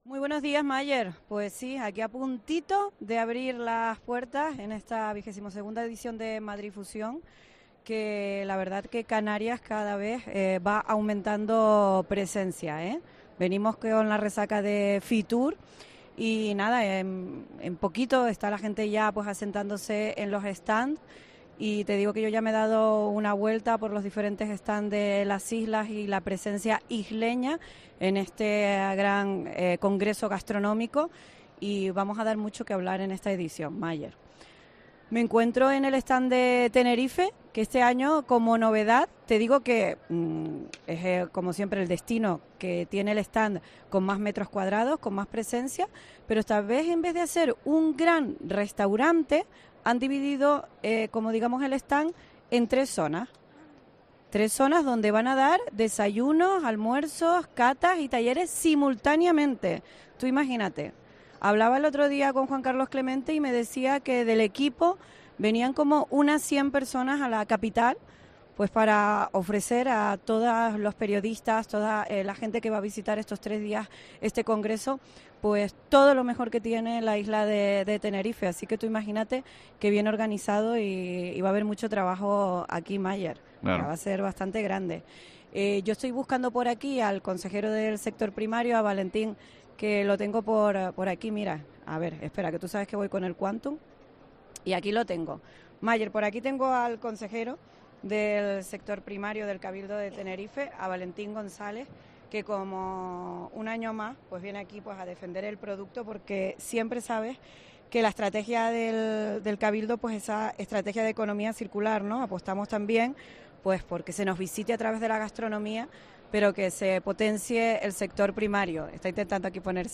Entrevista
en Madrid Fusión